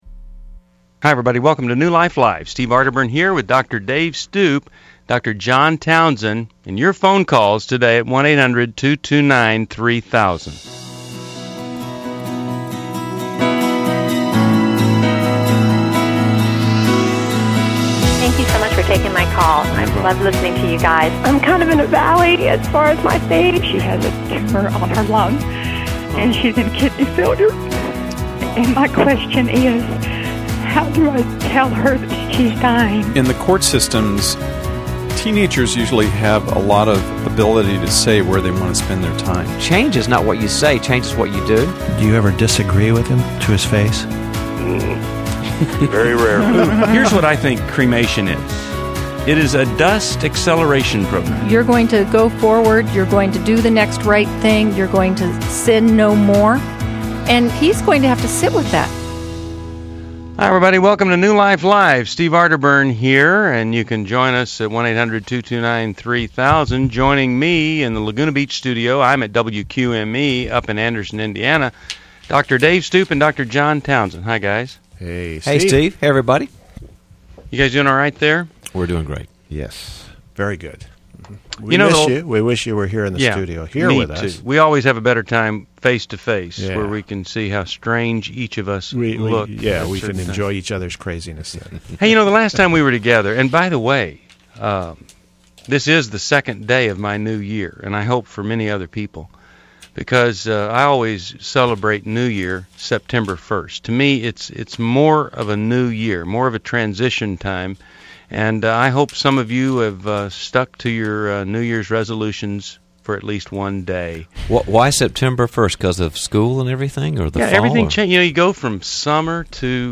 Explore deep emotional challenges on New Life Live: September 2, 2011, as experts tackle grief, transitioning, narcissism, and forgiveness in callers' lives.
Caller Questions: 1.